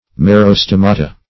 Merostomata \Mer`o*stom"a*ta\, prop. n. pl. [NL., fr. Gr. me`ros